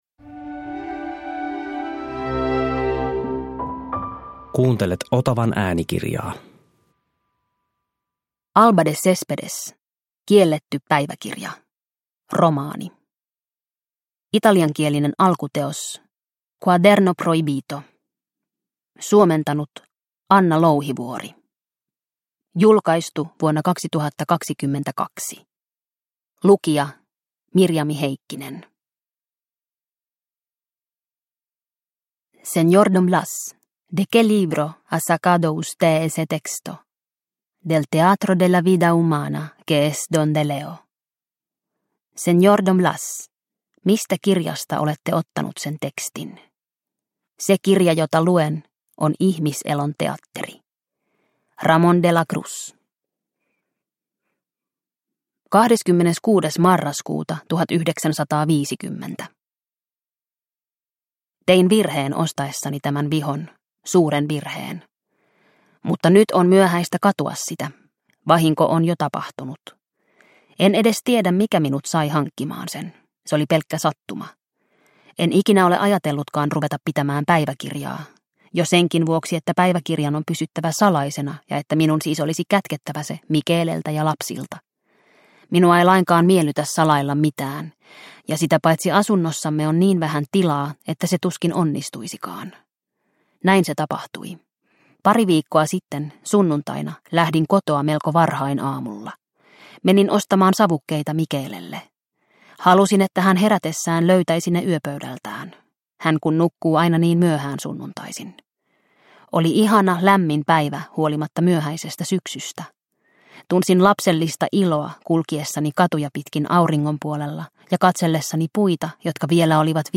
Kielletty päiväkirja – Ljudbok – Laddas ner